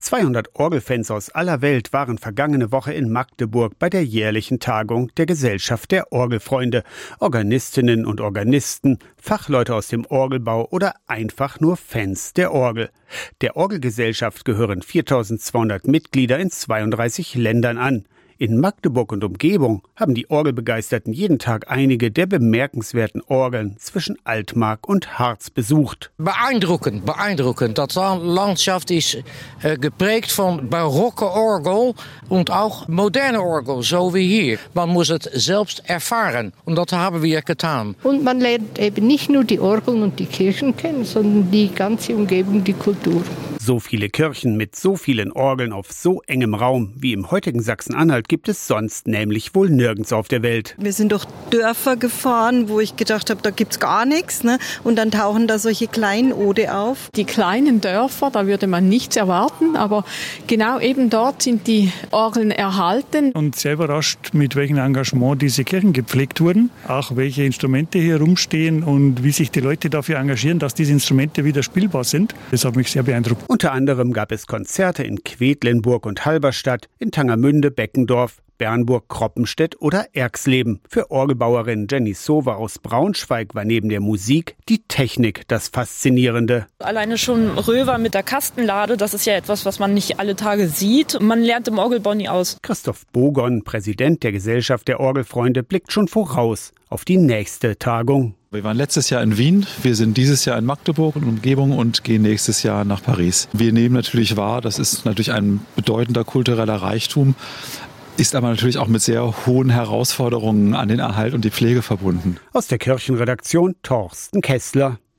71. Internationale Orgeltagung Magdeburg